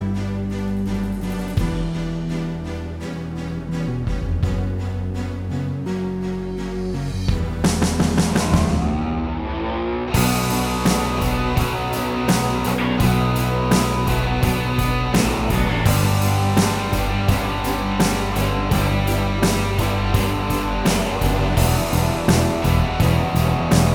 Minus All Guitars Rock 4:03 Buy £1.50